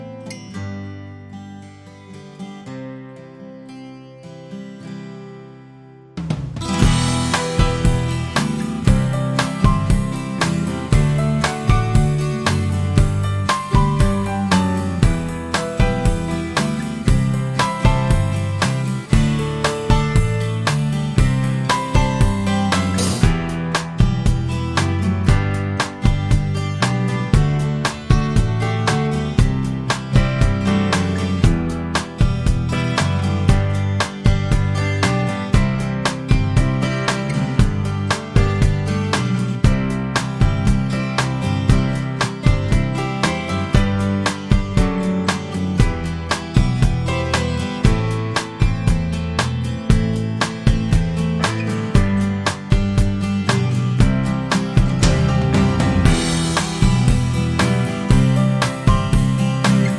No Harmonica And No Backing Vocals Rock 4:53 Buy £1.50